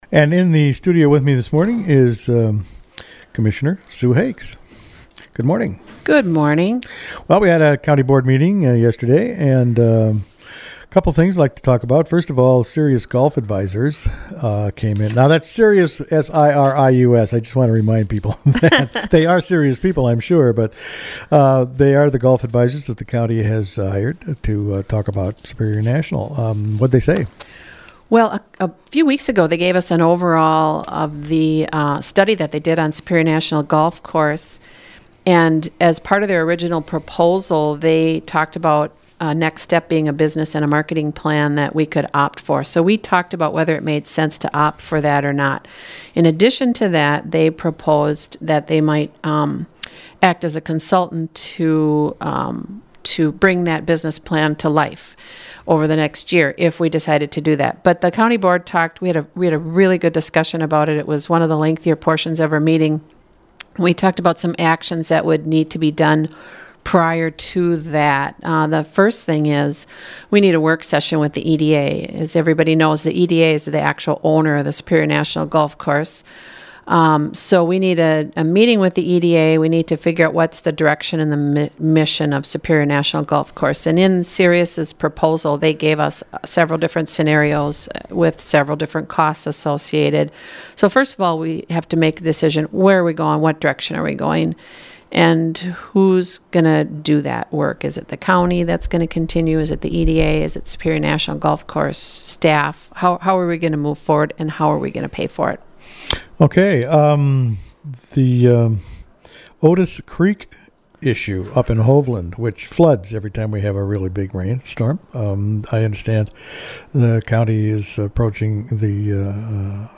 interviews Commissioner Sue Hakes.